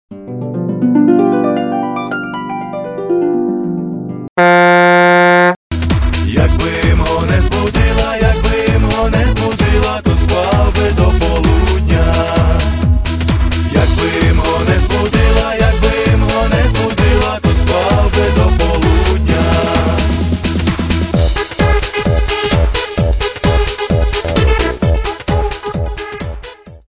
Тип: рінгтони